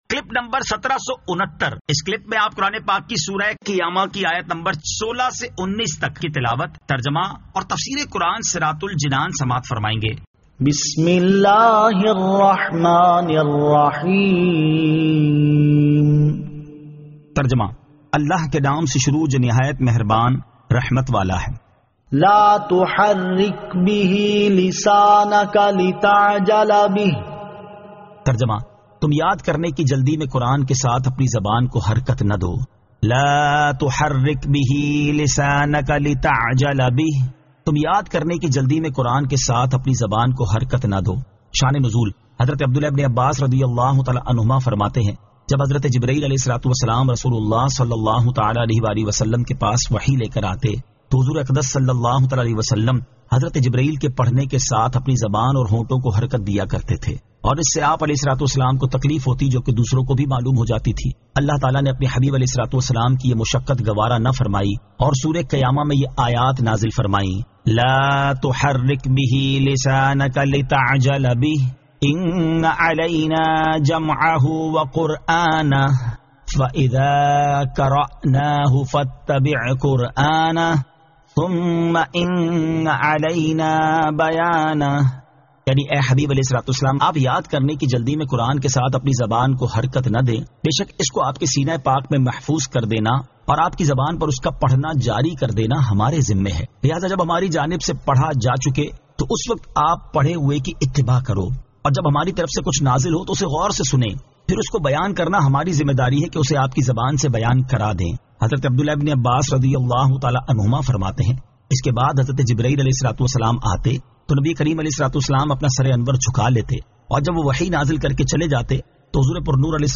Surah Al-Qiyamah 16 To 19 Tilawat , Tarjama , Tafseer